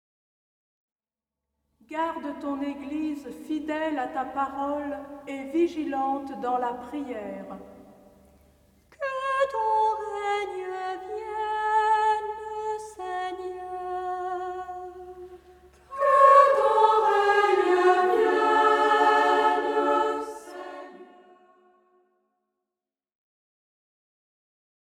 Lecture